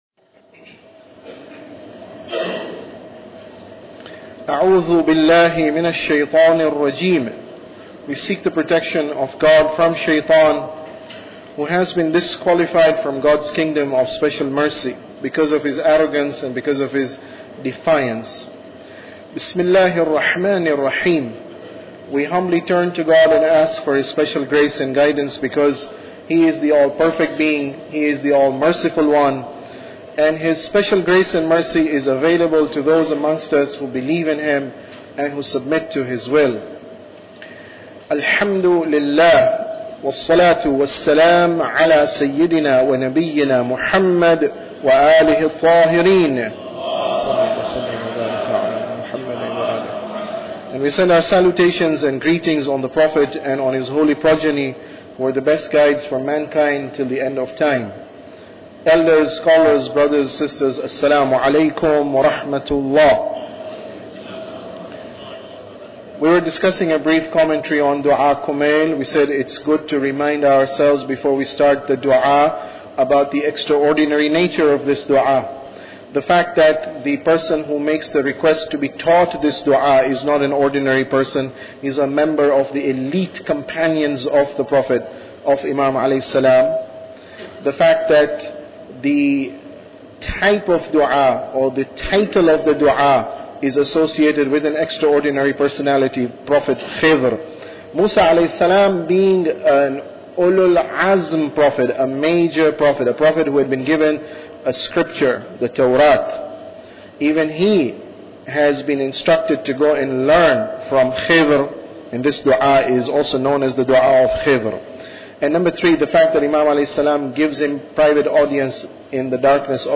Tafsir Dua Kumail Lecture 17